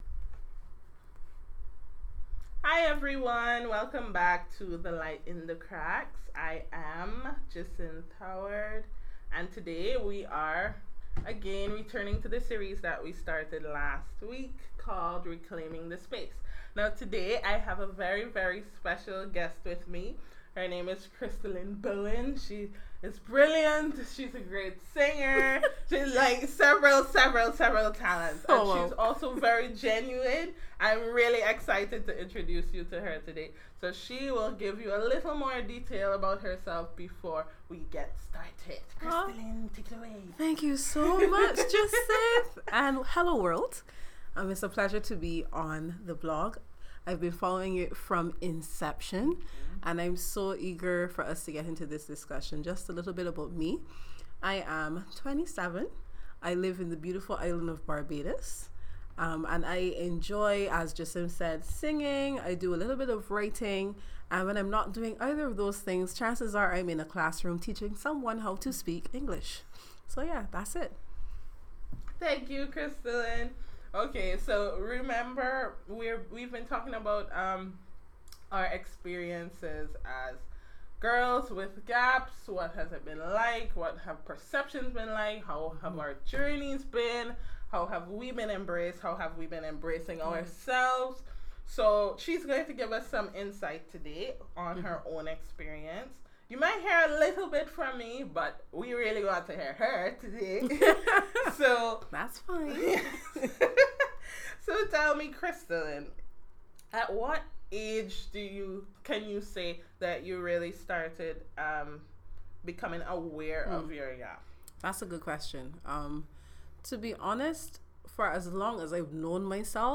This week features an interview with a vocal, strong, beautiful and brilliant young lady who has been instrumental in the fruition of some of our blog posts and who has been following from the beginning! We are so excited to share today her experiences as a girl with a gap.